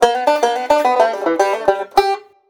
banjo.wav